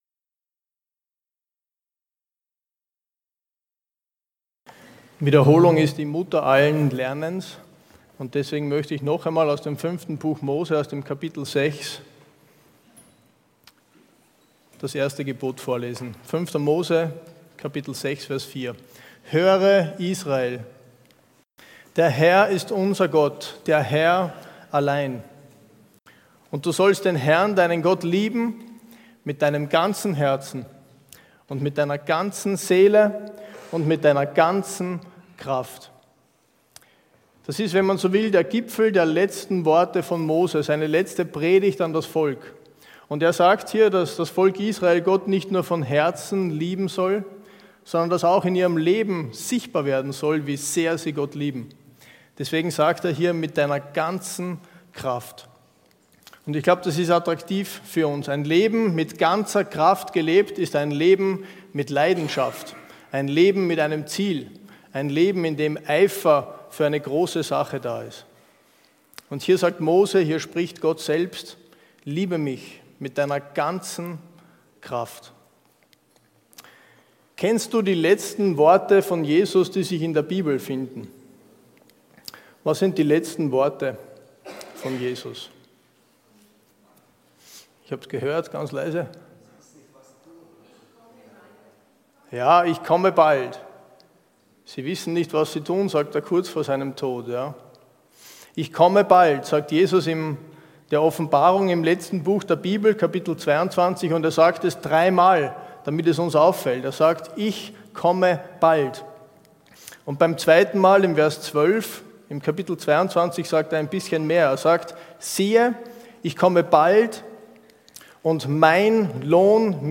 Predigtreihe: Matthäus